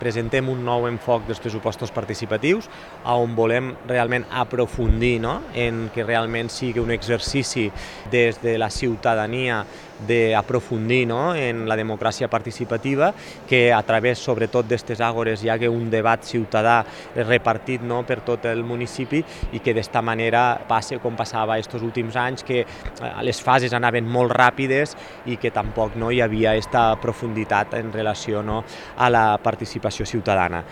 L’Alcalde de Tortosa, Jordi Jordan, ha destacat que és una forma d’implicar més a la ciutadania en la presa de decisions al municipi….